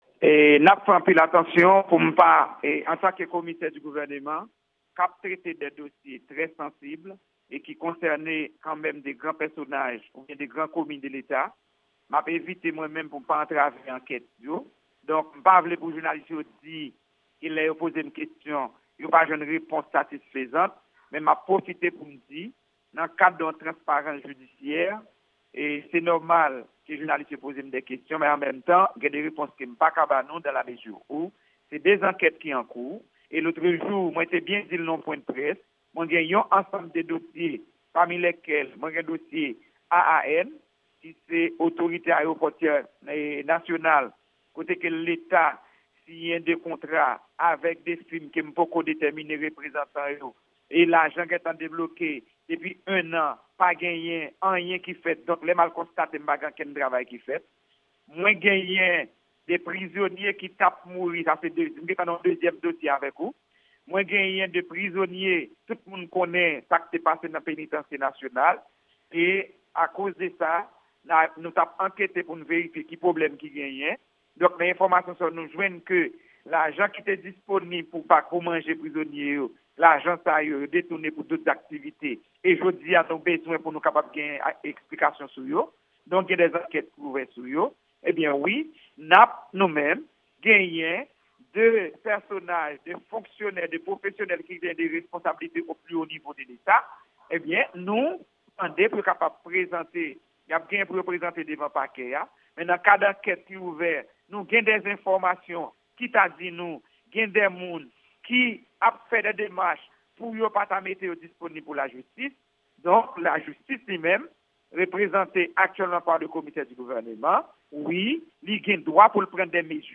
Entèvyou Lavwadlamerik ak chèf pakè Pòtoprens la sou entèdiksyon depa 2 ansyen minis yo